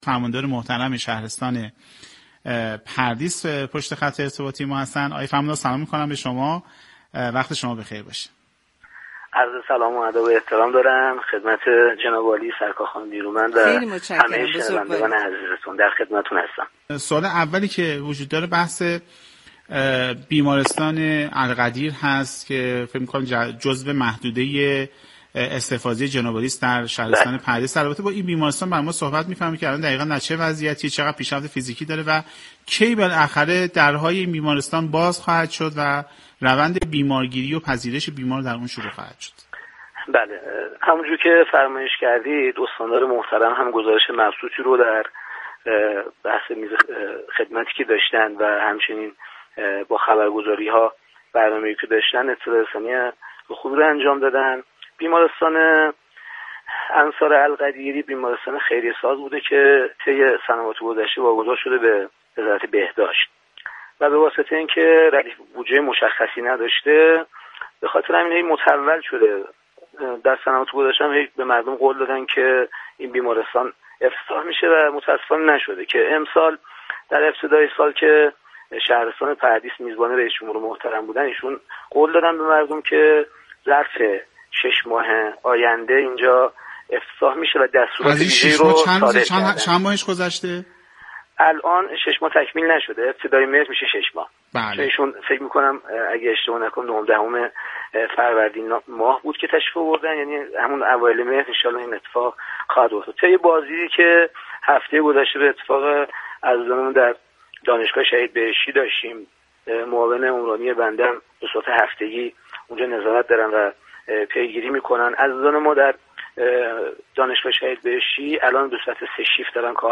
ظفر پورابراهیم فرماندار شهرستان پردیس به مناسبت هفته دولت درباره اقدامات این فرمانداری طی یك سال گذشته و اقدامات پیش رو با برنامه سعادت آباد 6 شهریور گفت و گو كرد.